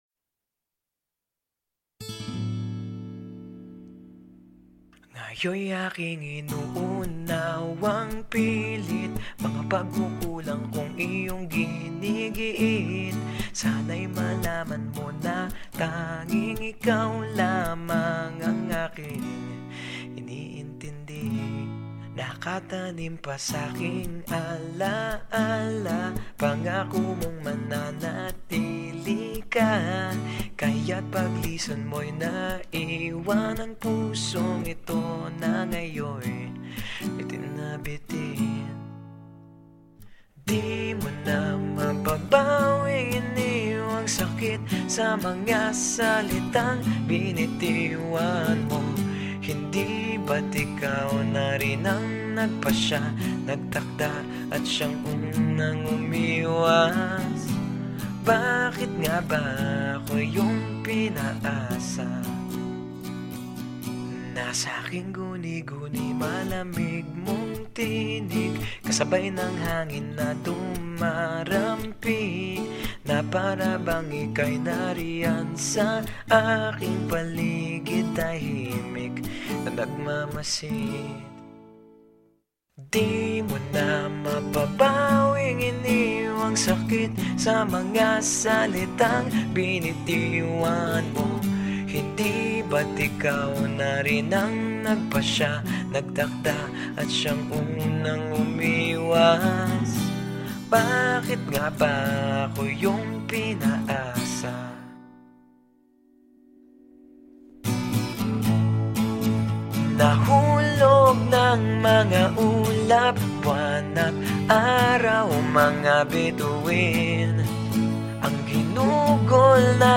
Category: Acoustic Music